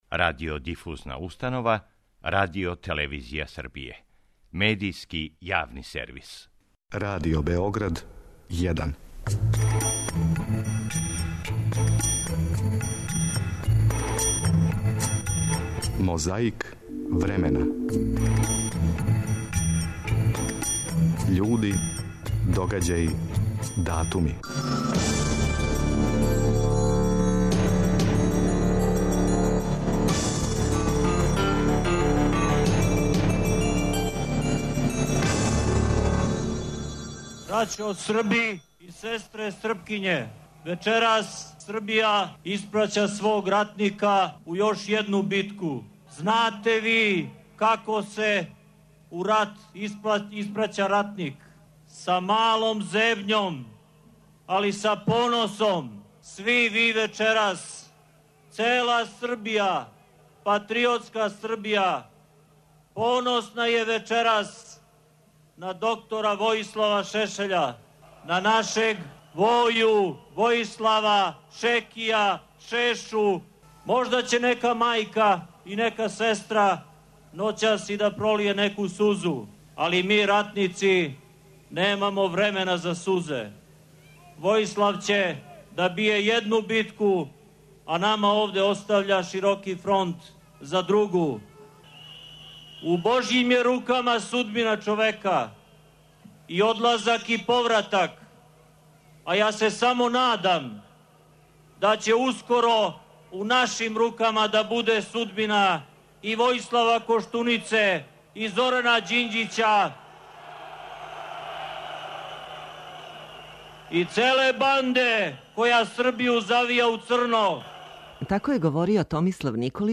У борби против пилећег памћења сетићемо се како је говорио Томислав Николић 23. фебруара 2003. када је Војислава Шешеља испраћао у Хаг.